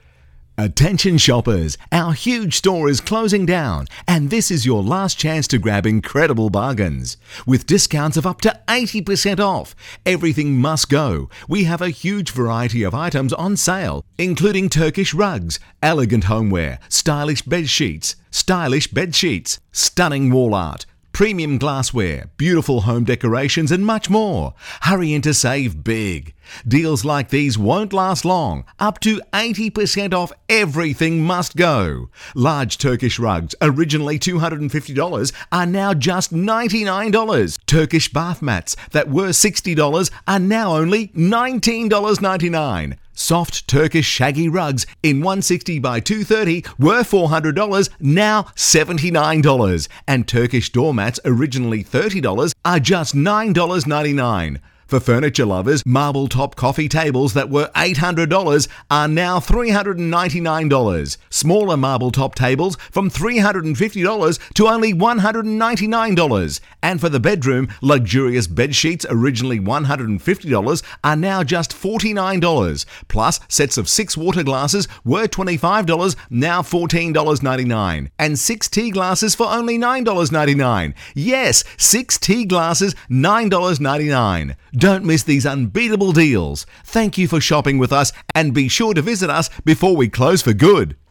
Professionally recorded phone messages
Male and female voice artists have clear enunciation and exceptional voice skills to convey your message in a friendly, pleasant manner.
Male voice - Style 1
Male-voice-style-1.mp3